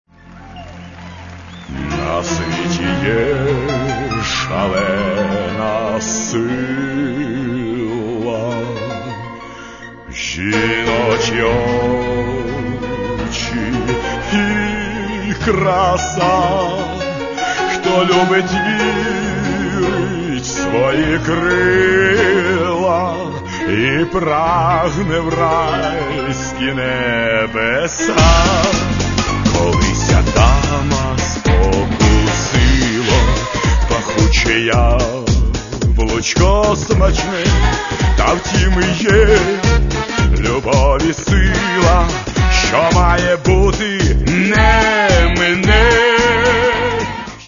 Каталог -> Естрада -> Дуети